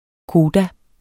Udtale [ ˈkoːda ]